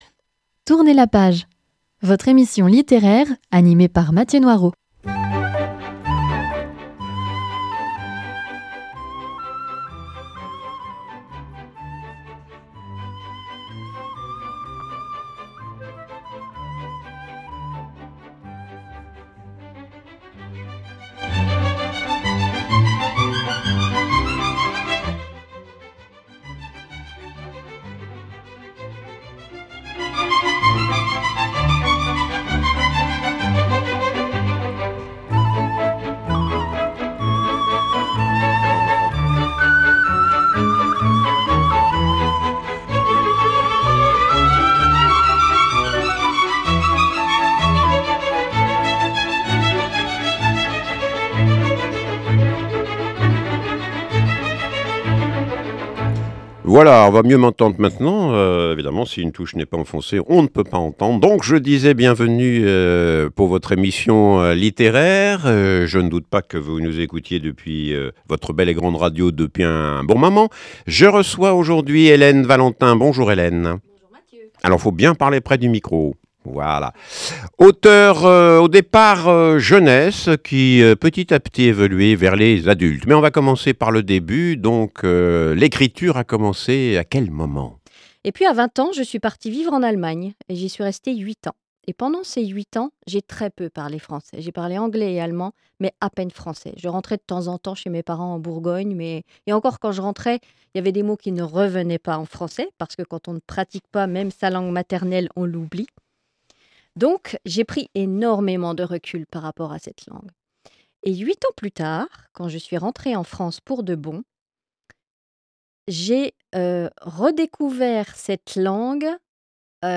Émission littéraire avec un·e invité·e : auteur ou autrice qui nous parle de son métier, de ses ouvrages ou de son dernier livre édité.